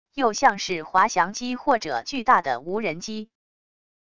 又像是滑翔机或者巨大的无人机wav音频